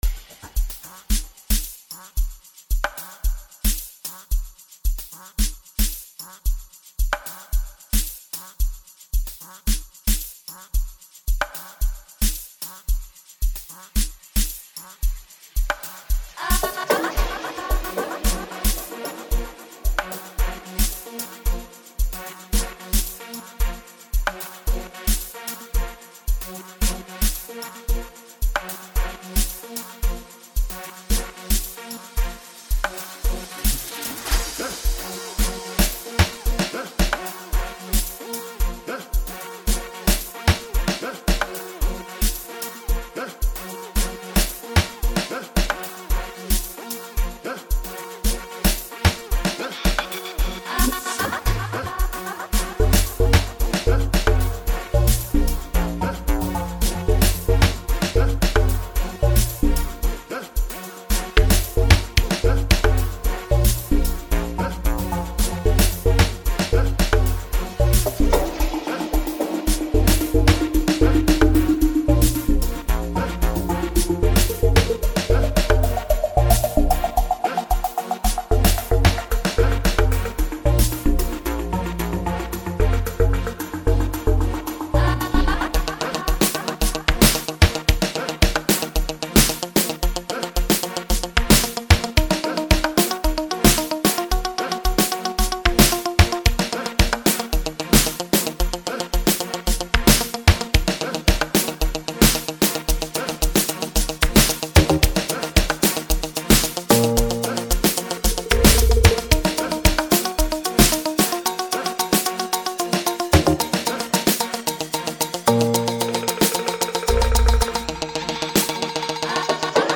Multi Talented South African Amapiano producer